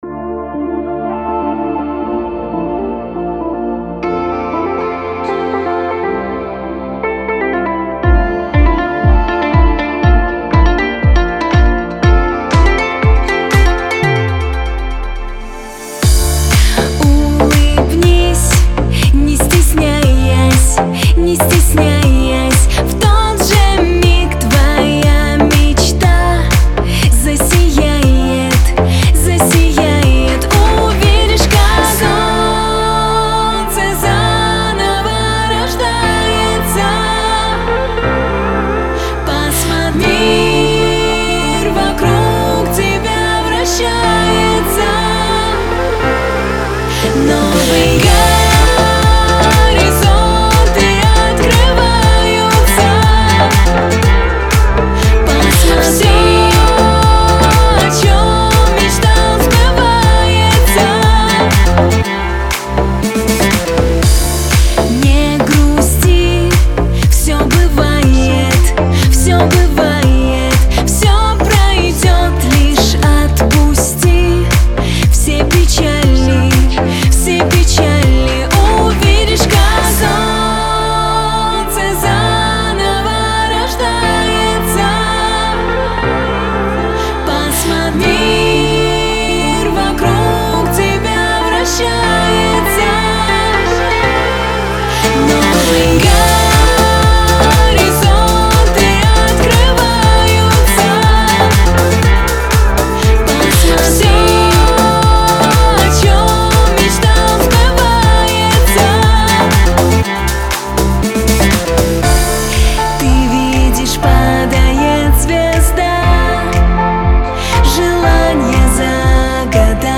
отличается легкими и запоминающимися мелодиями